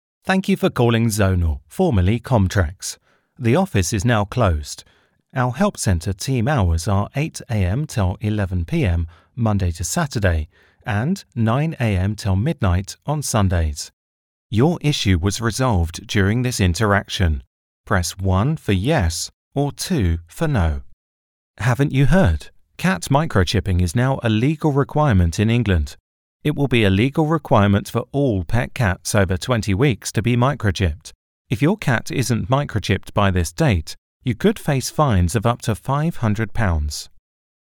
Inglés (Británico)
Natural, Amable, Empresarial, Comercial, Cálida
Telefonía